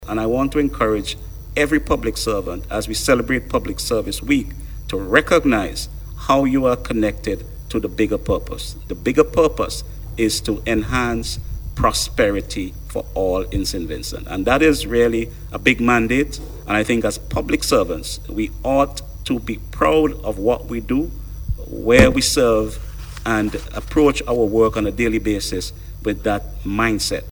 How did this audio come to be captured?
He made the point during a Panel Discussion held on Tuesday, as part of activities to commemorate Public Service Week.